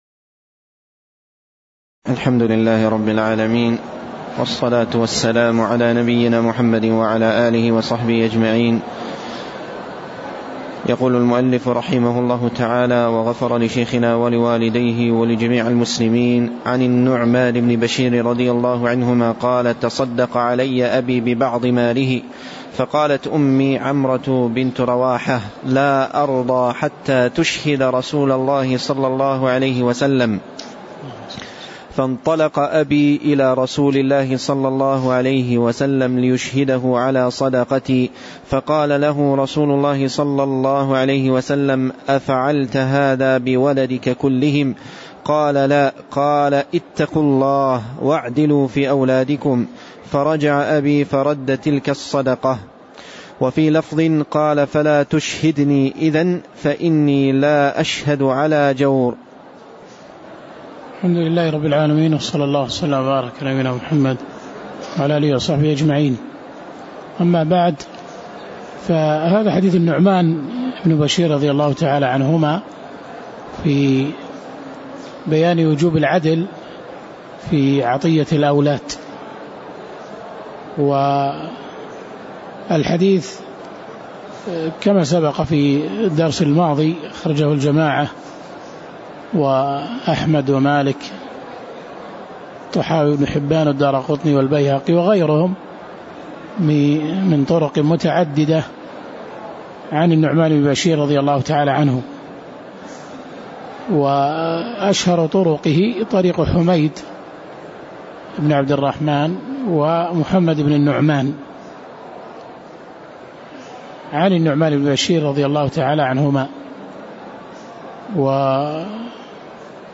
تاريخ النشر ٢٥ جمادى الأولى ١٤٣٩ هـ المكان: المسجد النبوي الشيخ